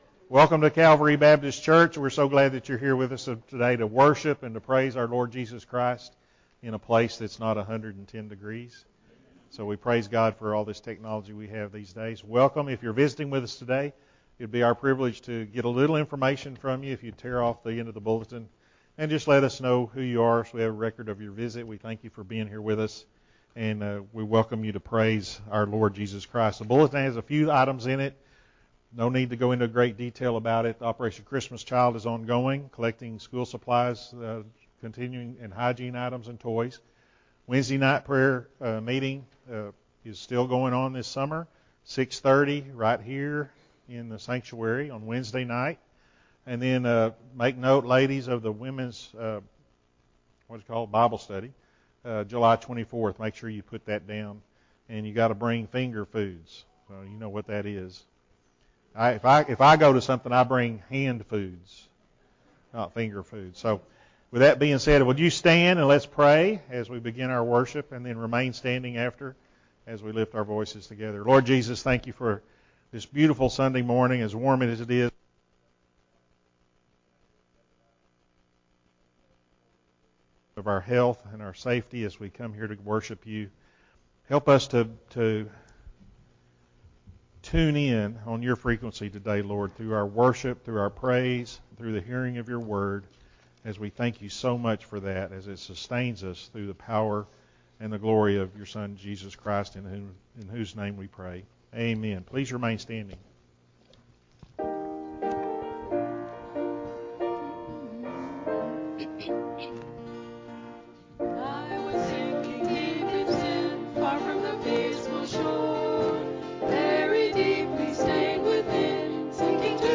Sunday Morning Sermon
Full Service Audio